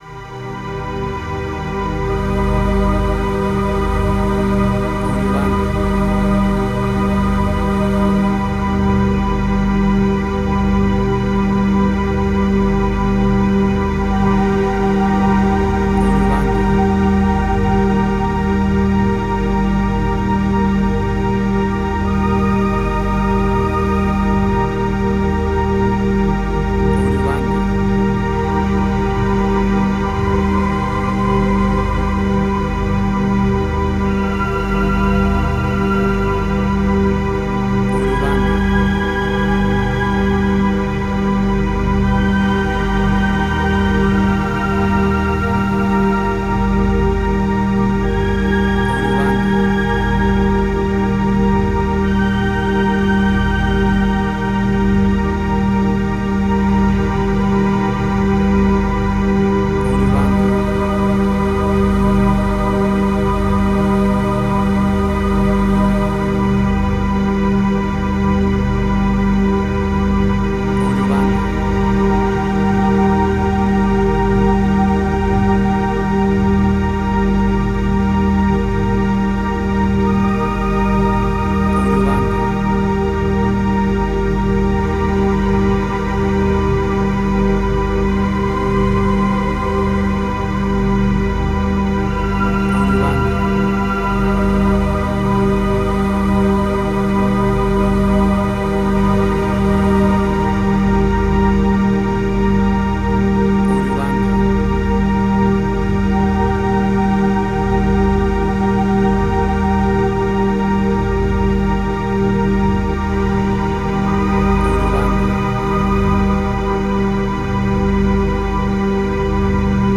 WAV Sample Rate: 32-Bit stereo, 44.1 kHz